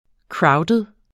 Udtale [ ˈkɹɑwdəð ]